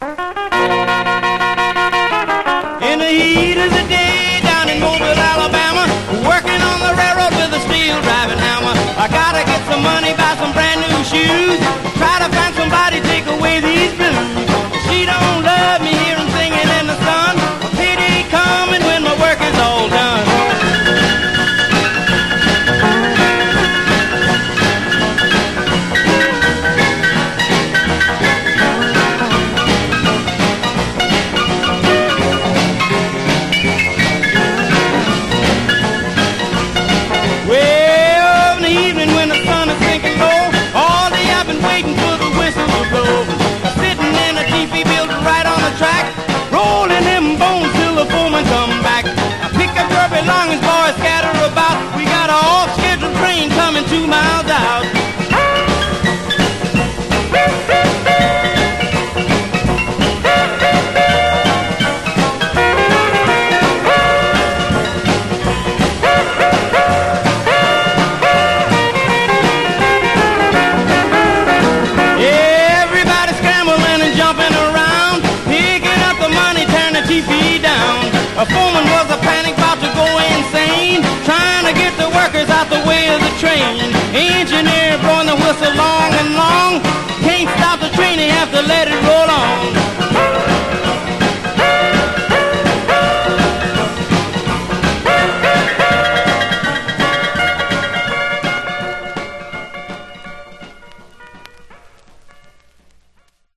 Genre: Rock 'n' Roll